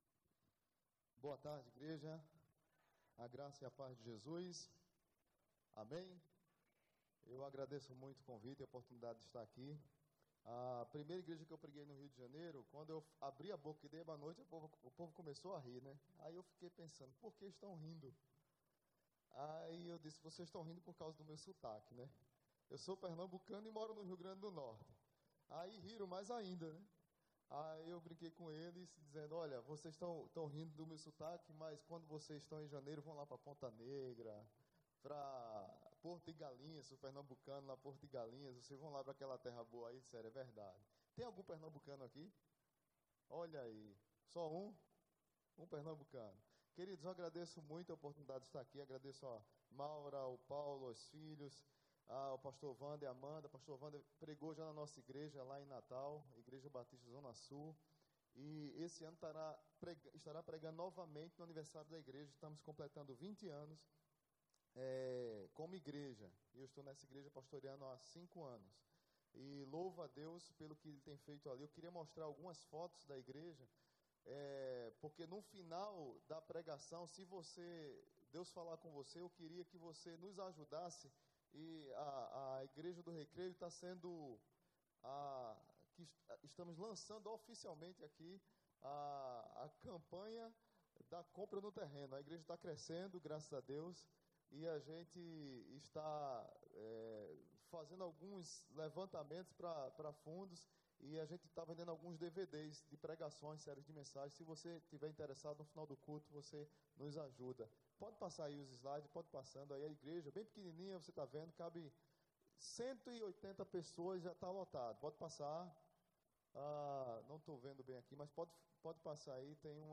Mensagem
na Igreja Batista do Recreio.